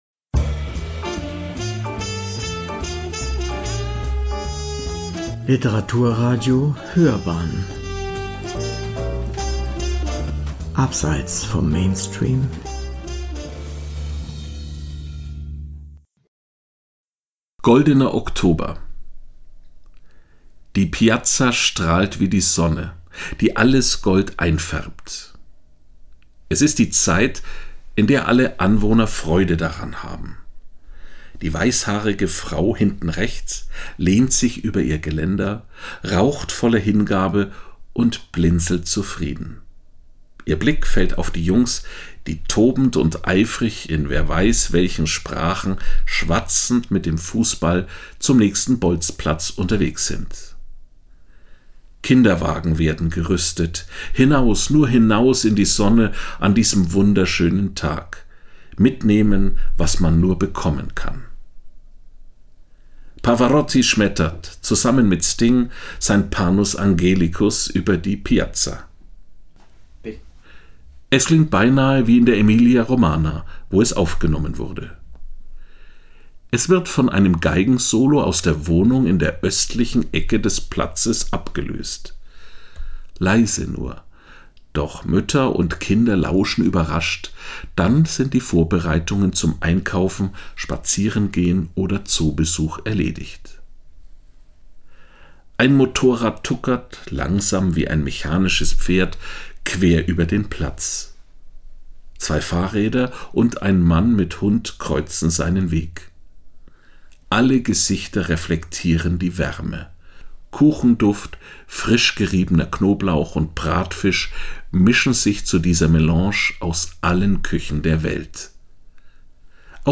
Piazza an der Bo “Goldener Oktober” – Kolumne